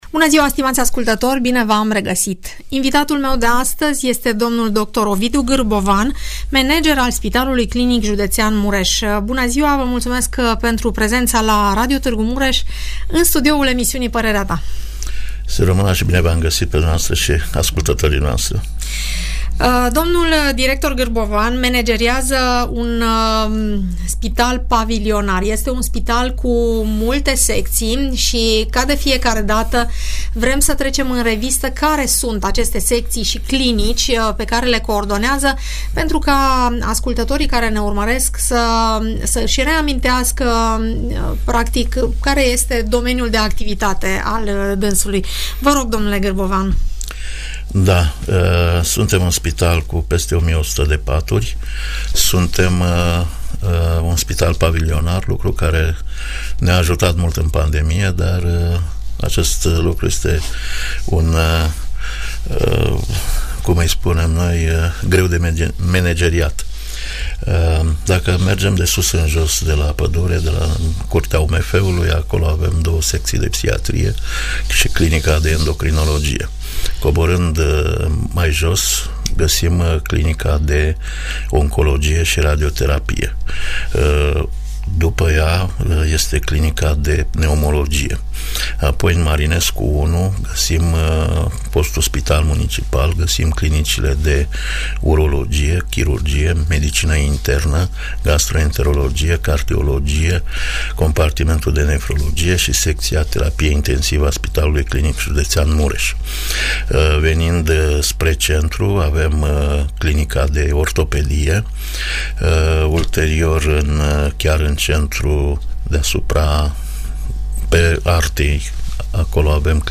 atragerea fondurilor pentru modernizarea unor secții și răspunde la întrebările ascultătorilor.